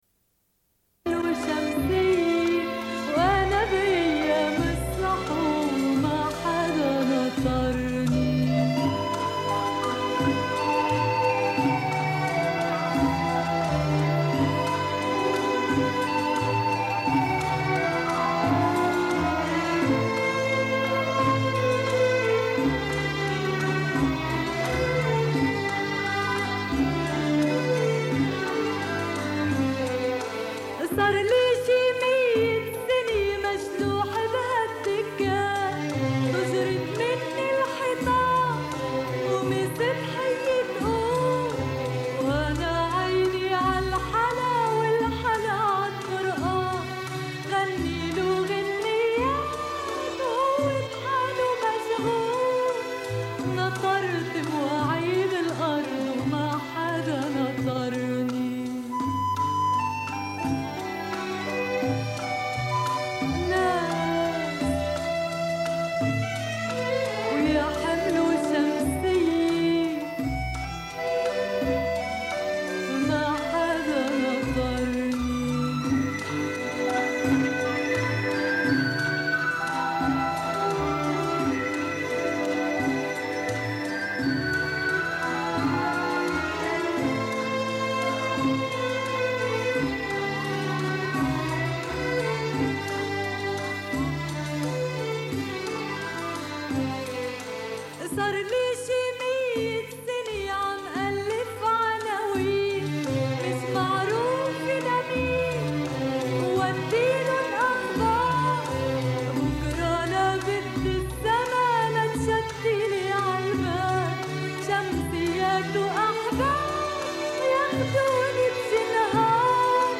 rediffusion d'une émission en direct
Une cassette audio, face B
Radio